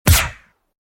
sfx_handgun_shoot_0.mp3